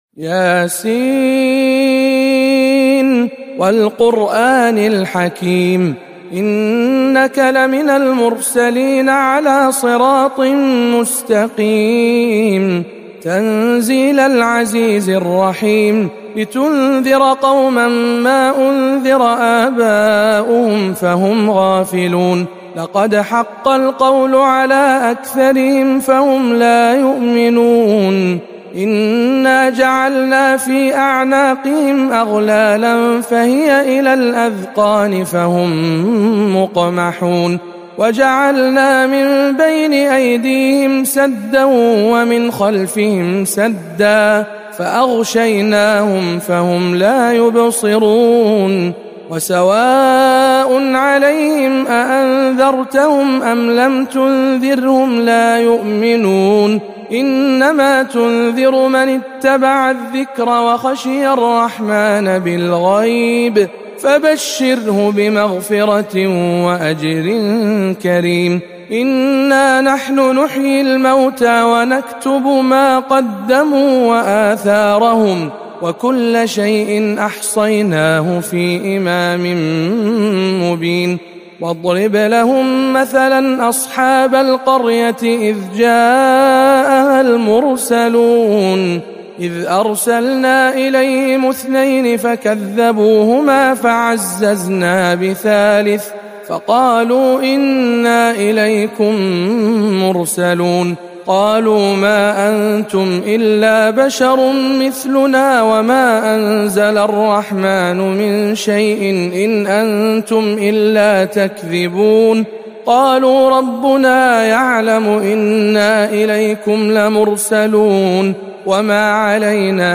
سورة يس بجامع معاذ بن جبل بمكة المكرمة